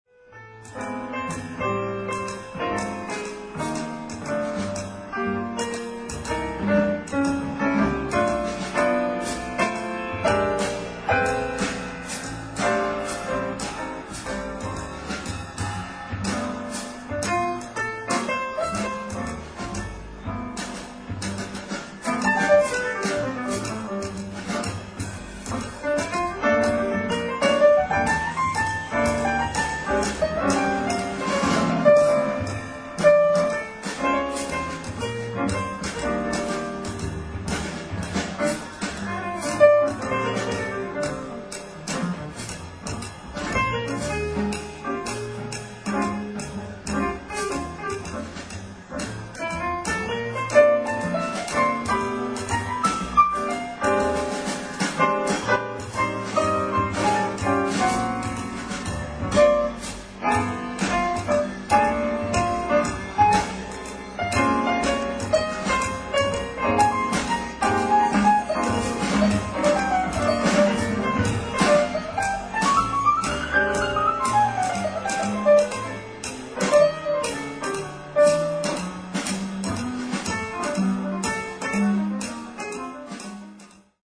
ライブ・アット・ペララーダ城音楽祭、ペララーダ、スペイン 07/20/2003
※試聴用に実際より音質を落としています。